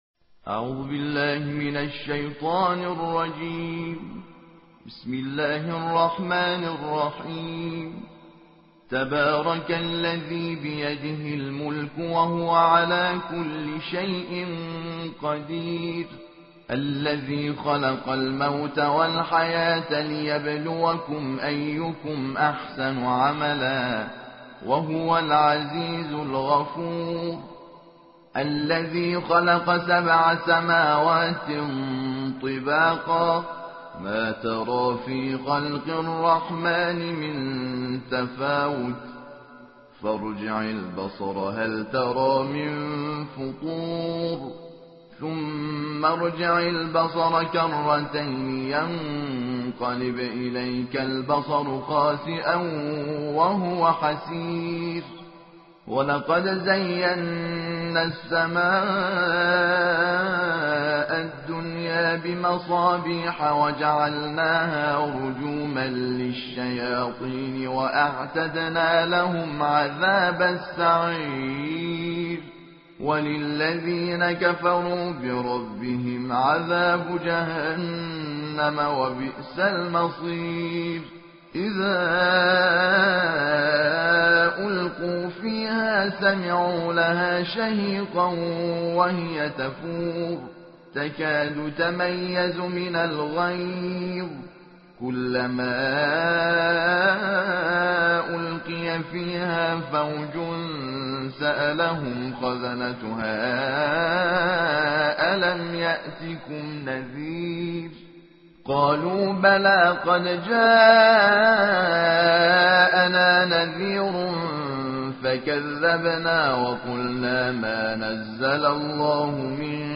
صوت | ترتیل جزء بیست و نهم قرآن کریم توسط"حامد شاکرنژاد"